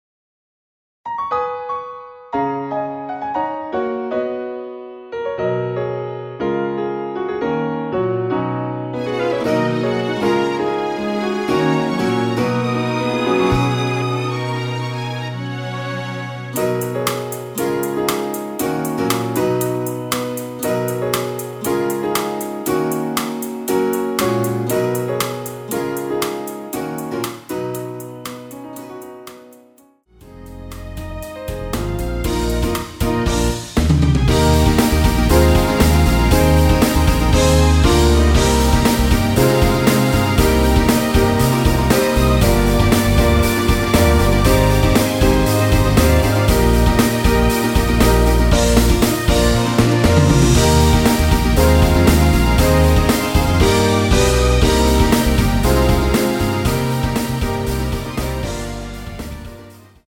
원키에서(+1) 올린 MR 입니다.(미리듣기 참조)
F#
앞부분30초, 뒷부분30초씩 편집해서 올려 드리고 있습니다.
중간에 음이 끈어지고 다시 나오는 이유는